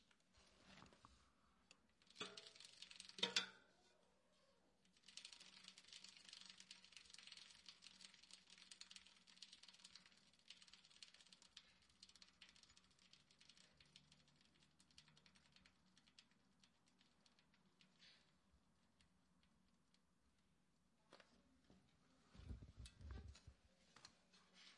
施工现场自行车（背面） 2
描述：施工现场自行车声音环境自然周围的现场录音 环境foley录音和实验声音设计。
Tag: 听起来 自行车 建筑 周边环境 网站 现场记录 自然